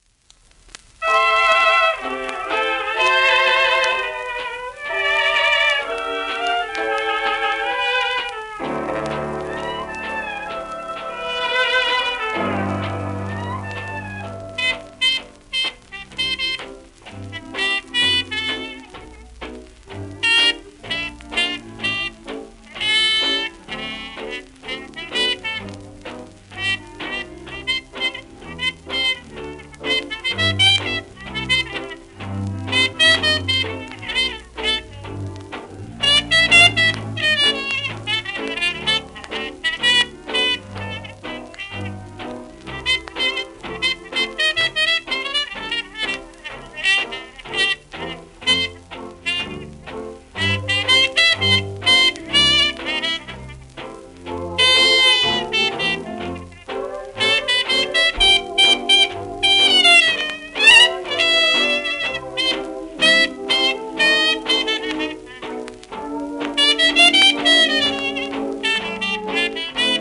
盤質B+/B *薄いエッジクラック(紙芯の影響による経年のもの音影響少)、面スレ、キズ